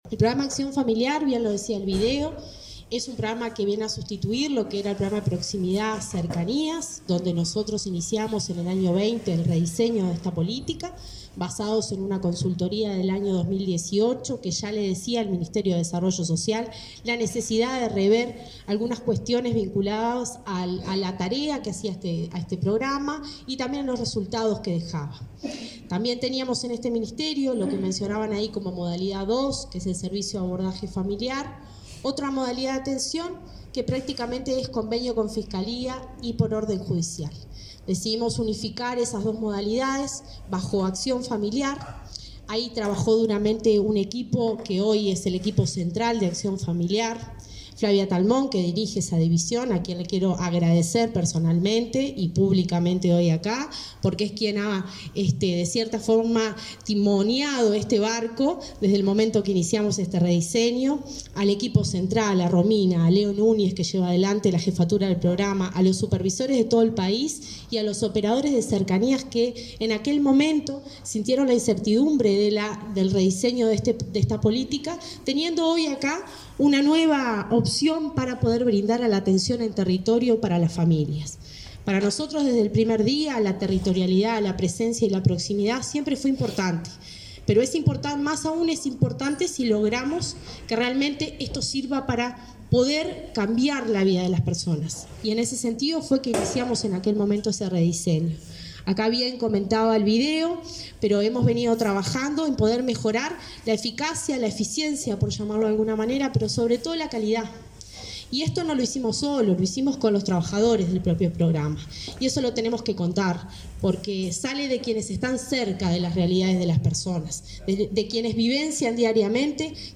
Palabras de autoridades en lanzamiento de nuevo programa del Mides
La directora nacional de Desarrollo Social, Cecilia Sena, y el ministro Martín Lema destacaron la importancia de esta iniciativa.